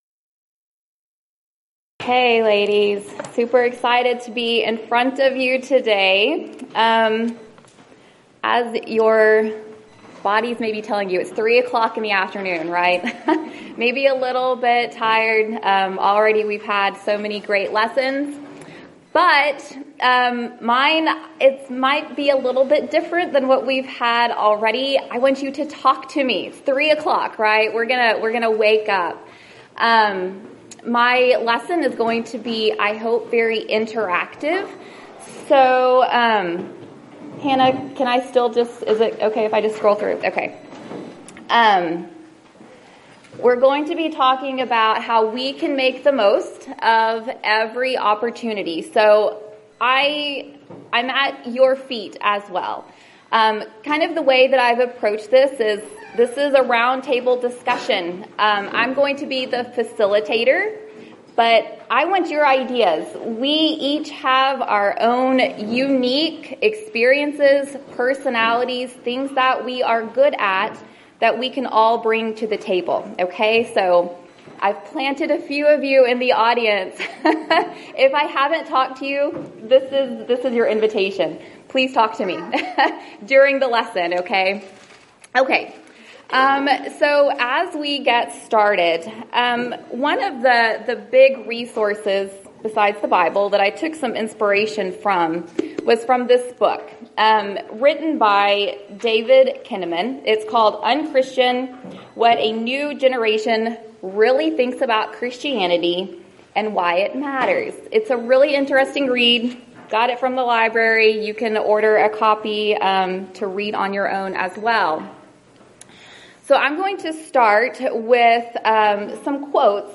Event: 5th Annual Arise Workshop Theme/Title: Arise and Evangelize
Ladies Sessions